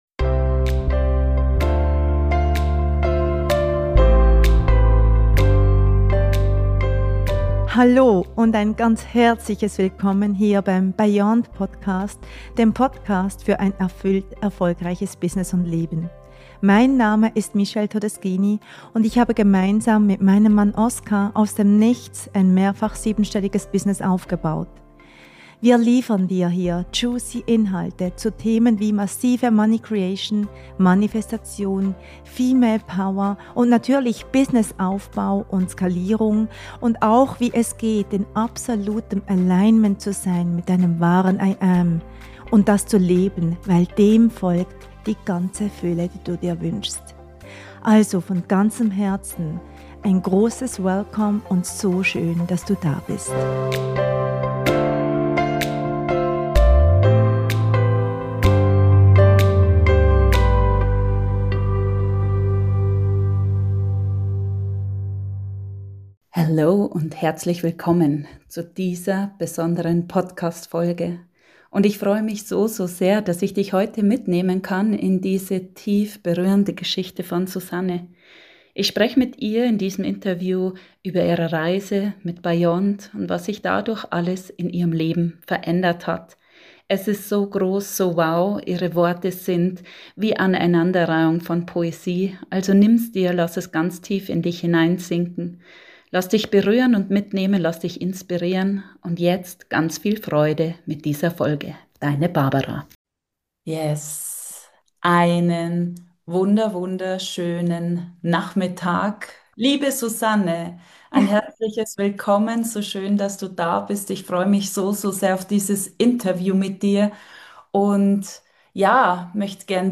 Erfolgsinterview